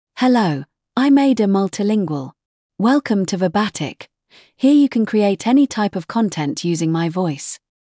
FemaleEnglish (United Kingdom)
Ada MultilingualFemale English AI voice
Voice sample
Listen to Ada Multilingual's female English voice.
Ada Multilingual delivers clear pronunciation with authentic United Kingdom English intonation, making your content sound professionally produced.